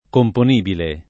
componibile [ kompon & bile ] agg.